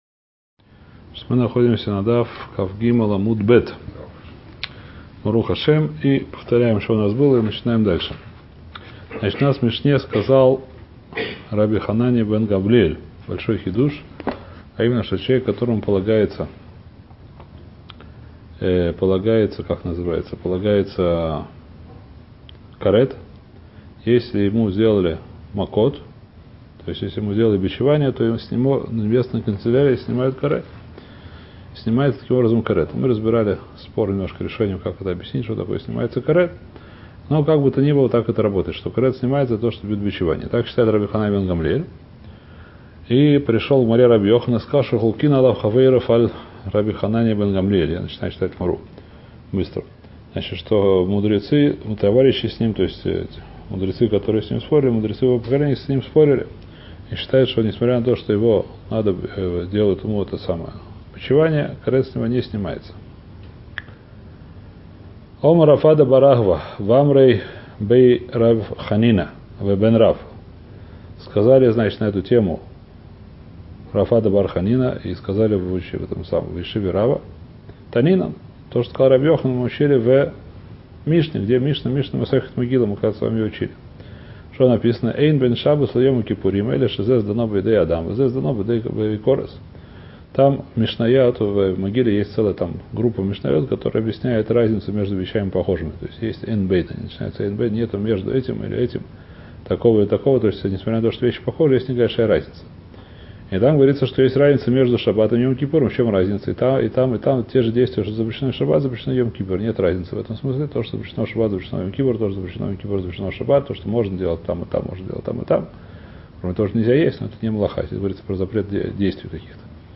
Трактат Макот — Урок 165 — Лист 23б